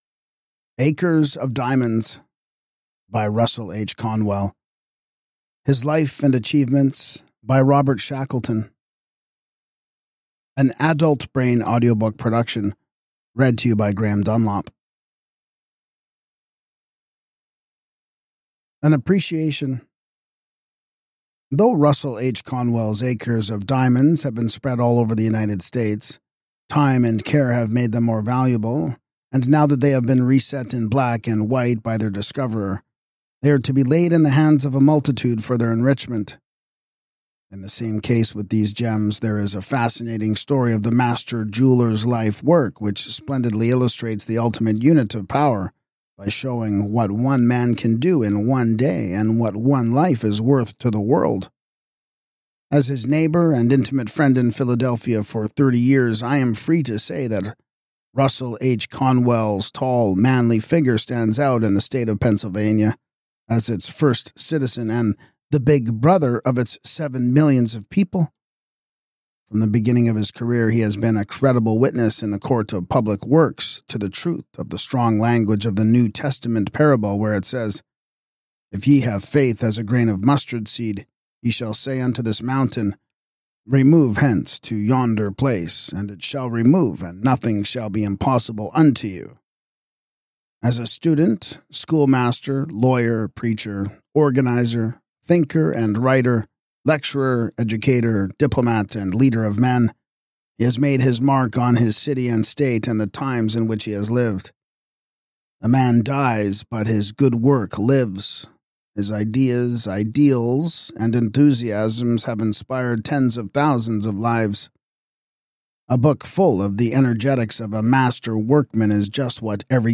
With its inspiring anecdotes and profound insights, this audiobook is a must-listen for anyone seeking motivation, personal development, and a deeper understanding of how to seize the opportunities already surrounding them.